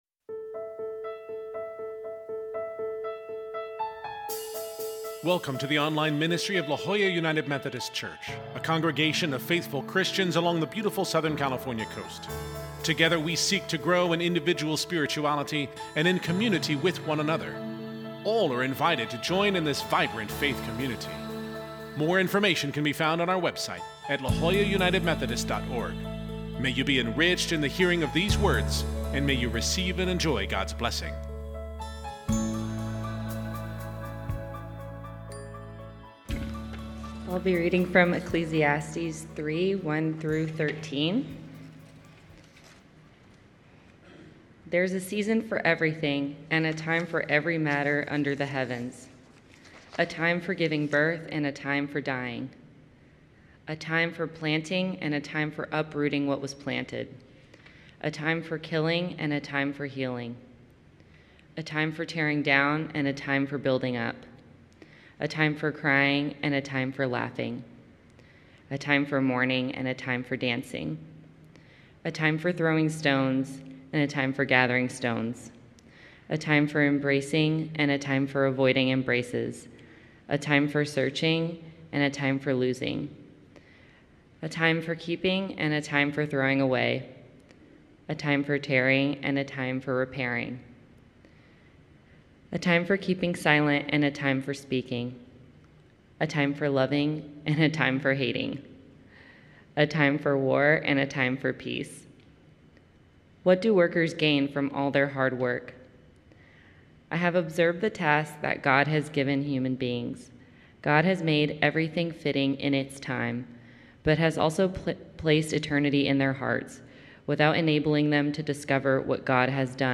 Our New Year’s Day service brought a familiar text and music as we begin a new year looking both forward and backward… Scripture: Ecclesiastes 3:1-13 (CEB)
Scripture: Ecclesiastes 3:1-13 (CEB) worship bulletin Sermon Note Share this: Print Twitter Facebook Audio (MP3) 17 MB Previous Inconceivable!